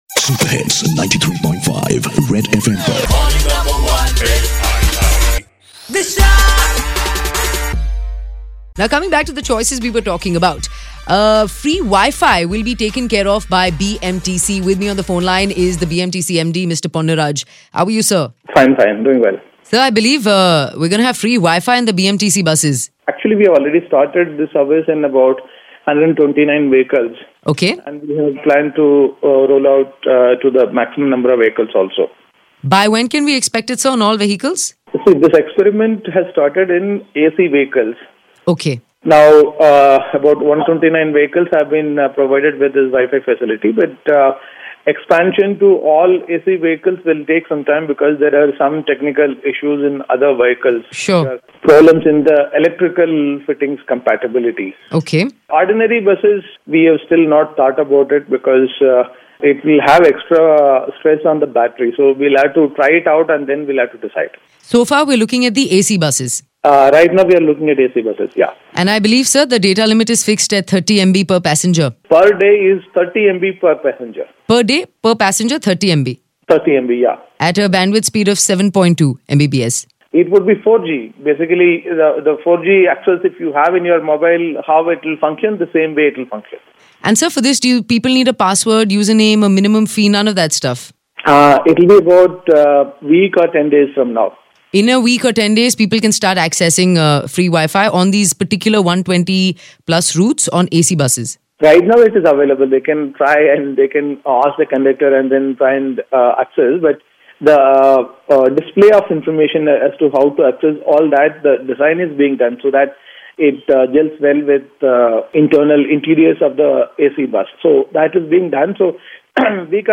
BMTC Chief Ponnuraj tunes in to the show to give details of free wifi in 129 AC Buses